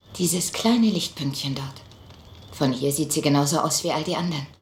sinniert als Cutler über die Sonne.